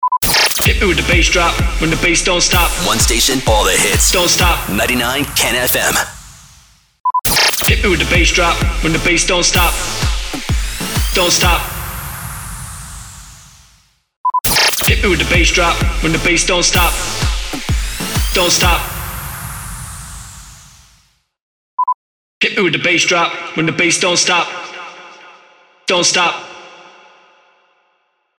375 – SWEEPER – BASS DROP
375-SWEEPER-BASS-DROP.mp3